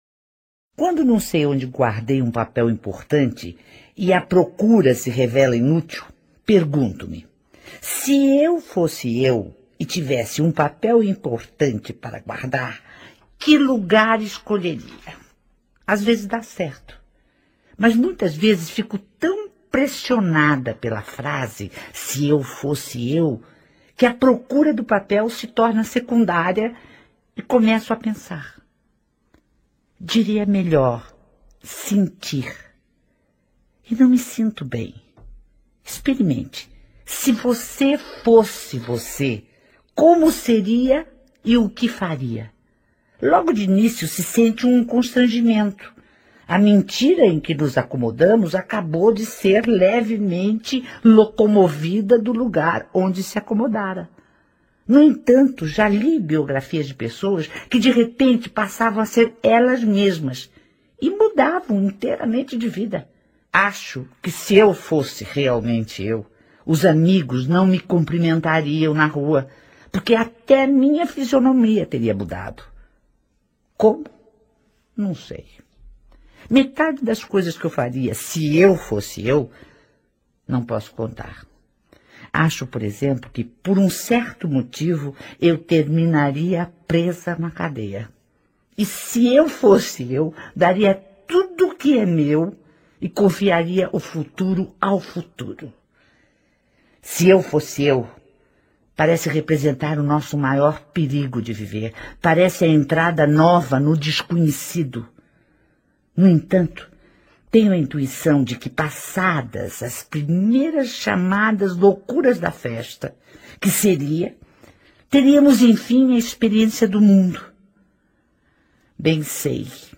Narrado por Aracy Balabanian